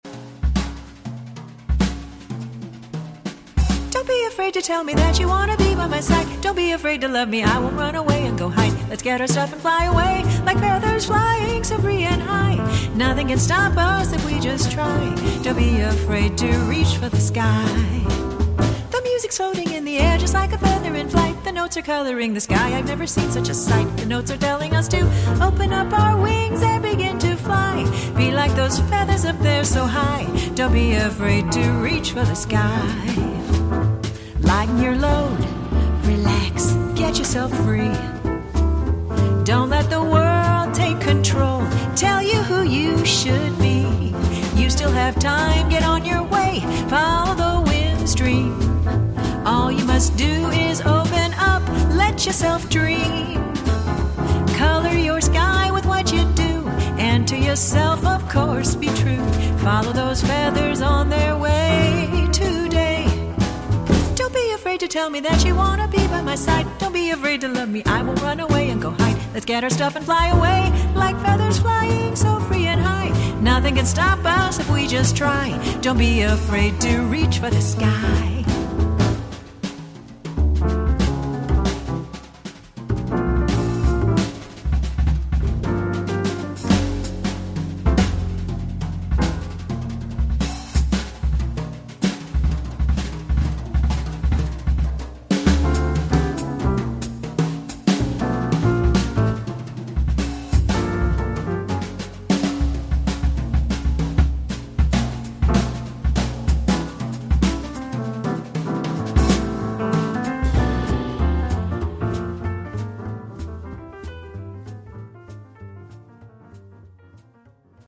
Another album of light jazz, blues, ballads, and bossas.